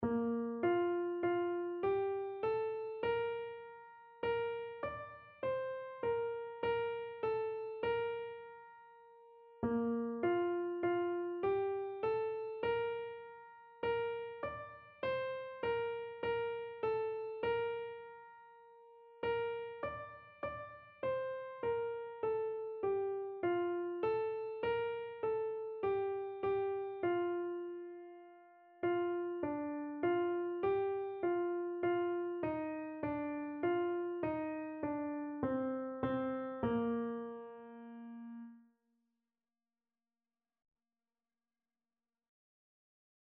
Christian Christian Keyboard Sheet Music All Glory, Laud, and Honor
Free Sheet music for Keyboard (Melody and Chords)
Bb major (Sounding Pitch) (View more Bb major Music for Keyboard )
4/4 (View more 4/4 Music)
Instrument:
Keyboard  (View more Intermediate Keyboard Music)
Classical (View more Classical Keyboard Music)